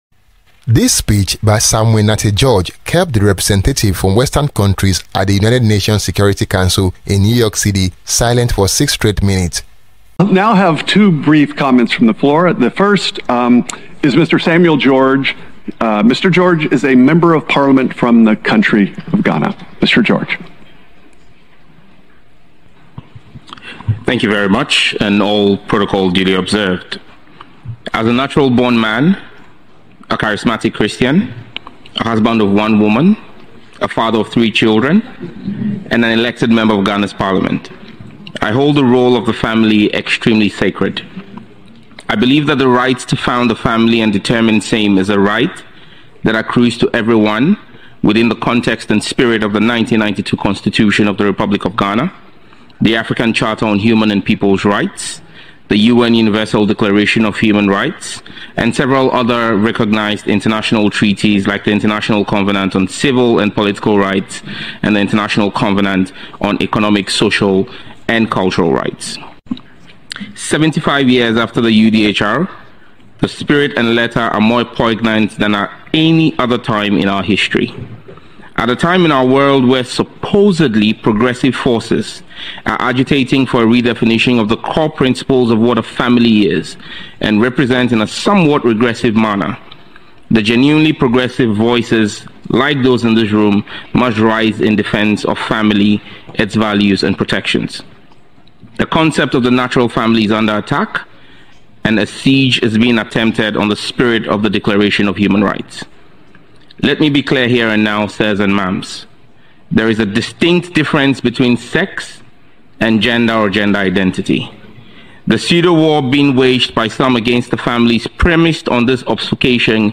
Fearless Ghanaian PM Sam George Just shocked the UN security council in New York city speech.
This speech by Samuel Nartey George kept the representative from western countries at the united nations security council in New York city silent for six straight minutes